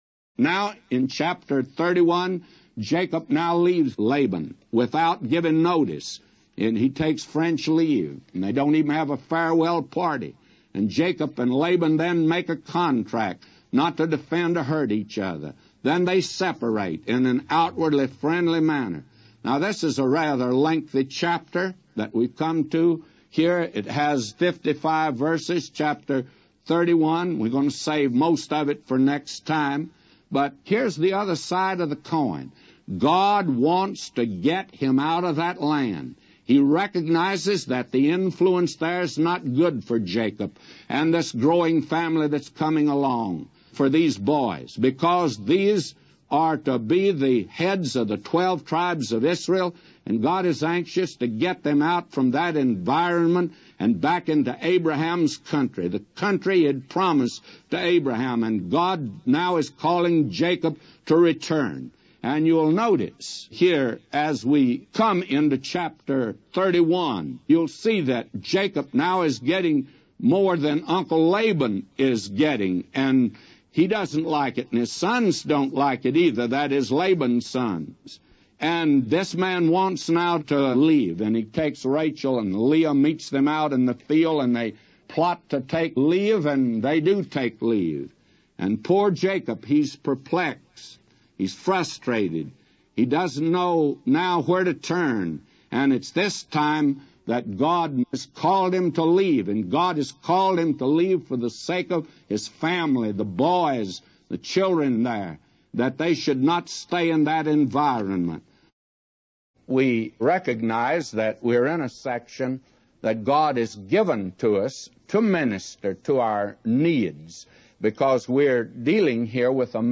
A Commentary By J Vernon MCgee For Genesis 31:1-999